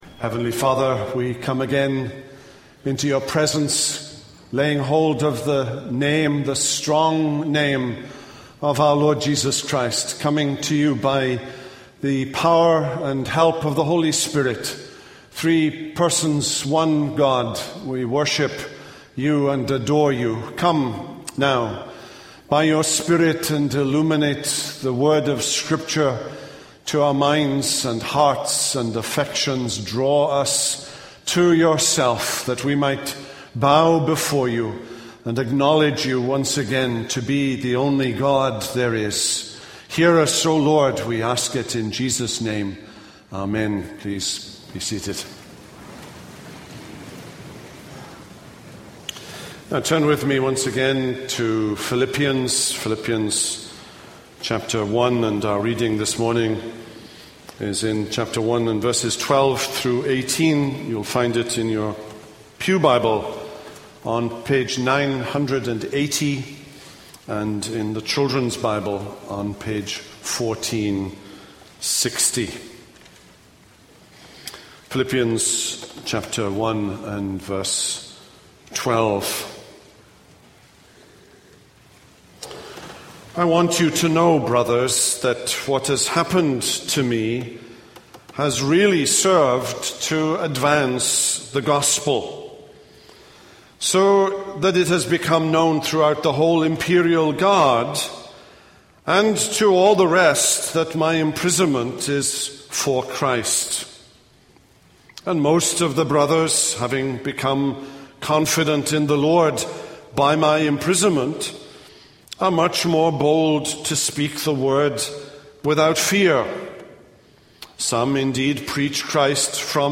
This is a sermon on Philippians 1:12-18a.